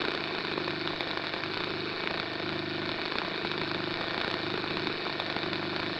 pgs/Assets/Audio/Sci-Fi Sounds/Hum and Ambience/Hum Loop 2.wav at master
Hum Loop 2.wav